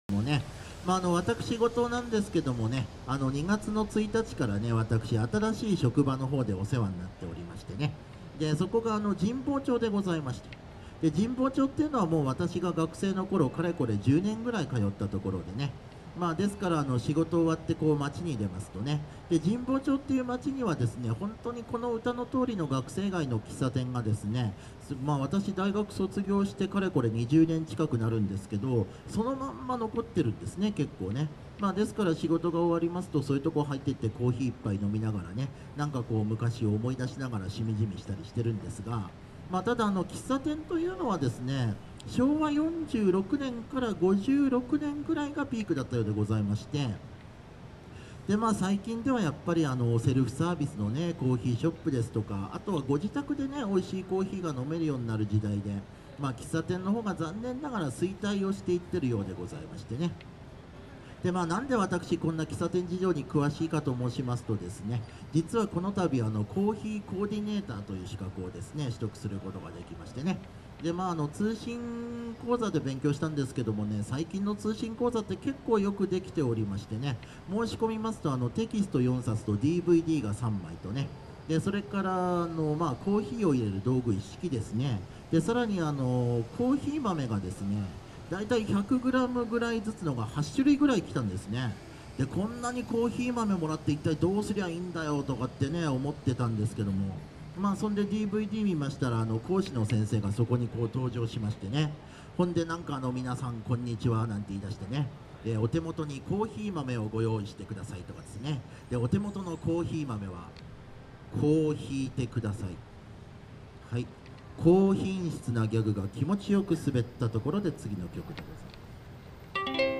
東京ストリートコーナーズ“ロジヨコライブ”レポート
トークデータはこちら（ウインドウズメディア形式）。